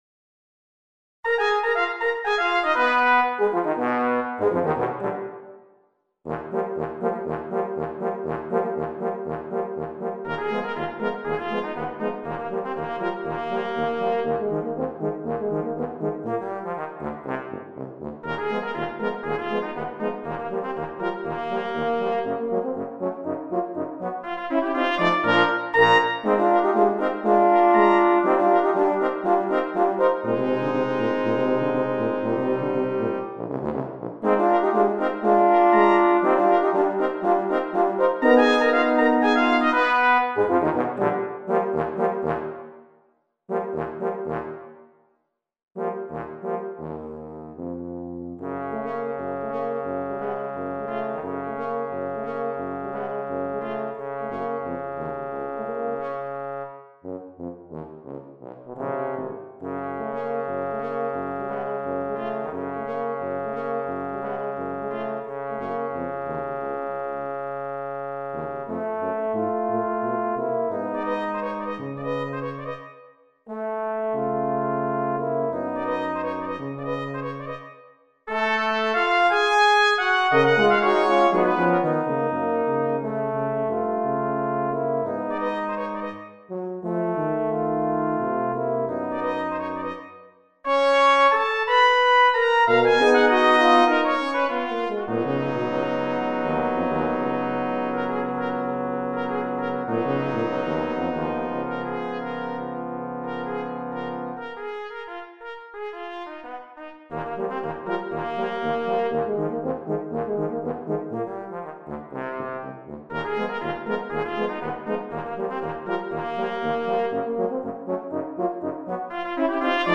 Quatuor de Cuivres